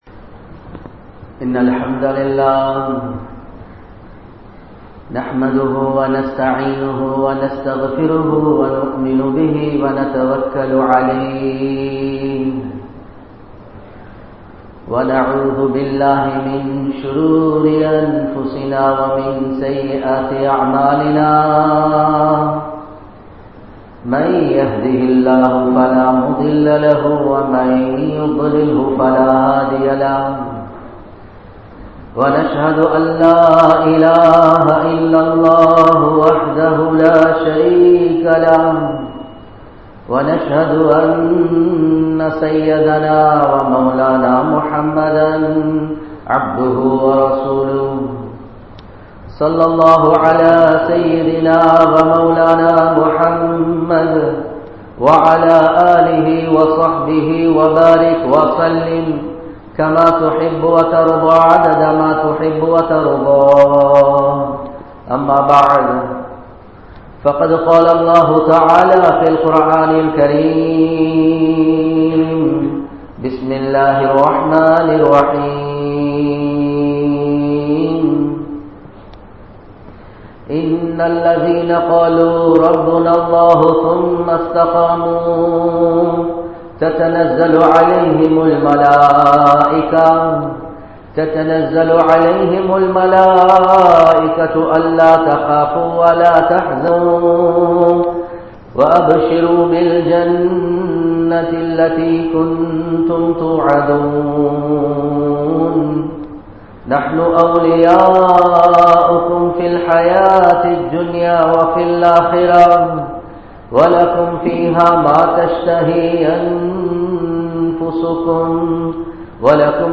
Thatpoathaiya Pirachchinaikku Kaaranam Yaar? (தற்போதைய பிரச்சினைக்கு காரணம் யார்?) | Audio Bayans | All Ceylon Muslim Youth Community | Addalaichenai